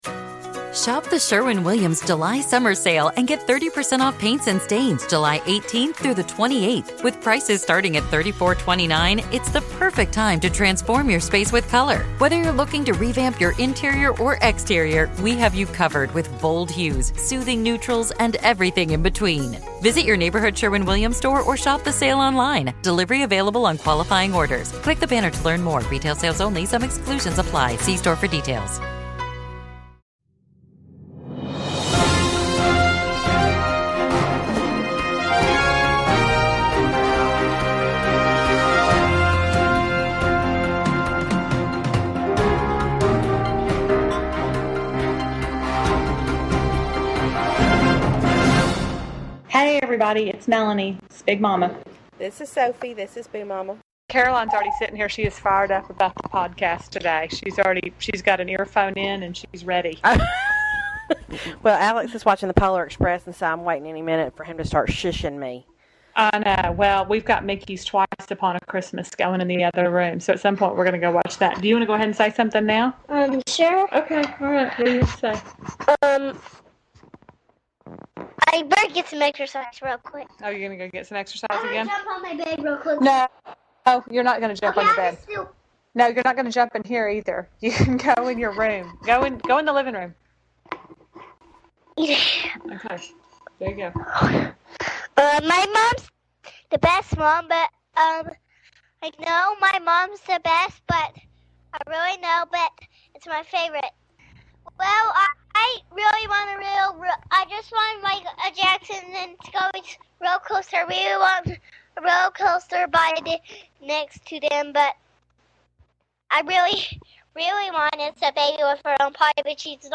And as such we are giving you yay, even MORE unstructured, unorganized ramblings from the two of us.